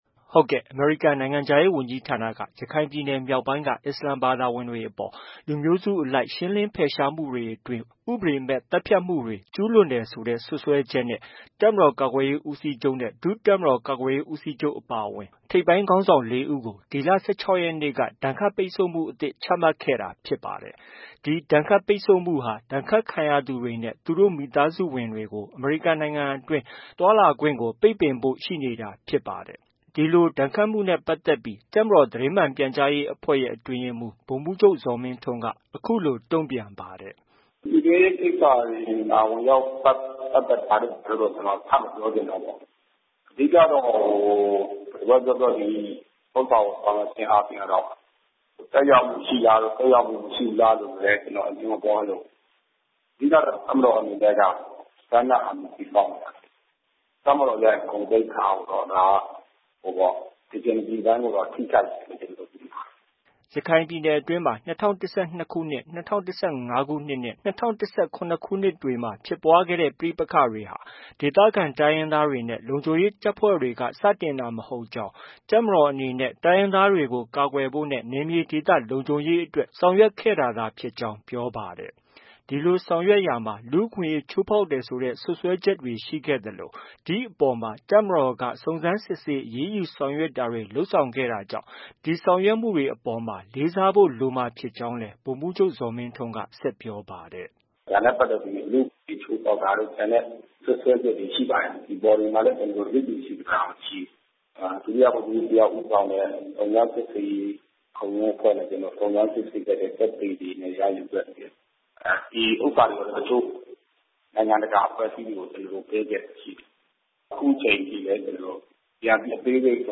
ဒီဒဏ်ခတ်ပိတ်ဆို့မှုဟာ ဒဏ်ခတ်ခံရသူတွေနဲ့ သူတို့မိသားစုဝင်တွေကို အမေရိကန်နိုင်ငံတွင်း သွားလာခွင့် ပိတ်ပင်သွားဖို့ရှိနေတာဖြစ်ပါတယ်။ ဒီလို ဒဏ်ခတ်မှုနဲ့ပတ်သက်ပြီး တပ်မတော်သတင်းမှန်ပြန်ကြားရေးအဖွဲ့ရဲ့ အတွင်းရေးမှူး ဗိုလ်မှူးချုပ် ဇော်မင်းထွန်းက အခုလို တုံ့ပြန်ပါတယ်။